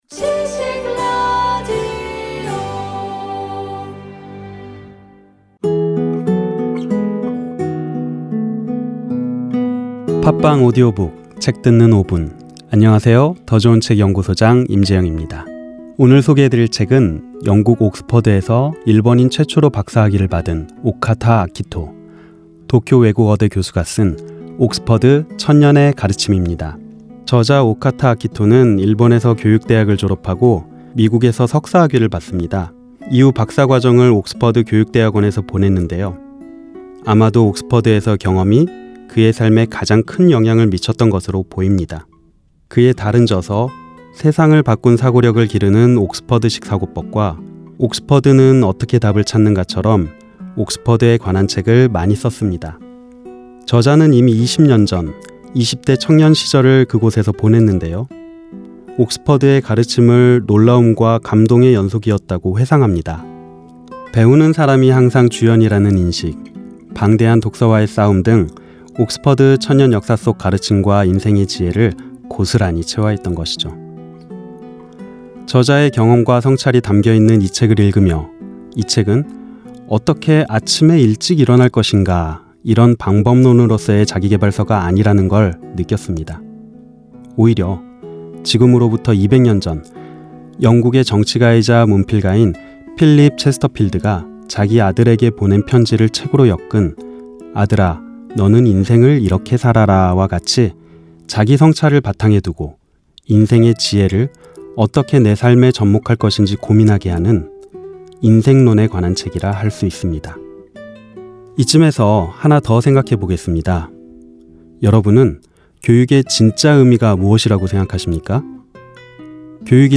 팟빵오디오북, <책 듣는 5분>